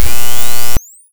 zappy sound.
spark.ogg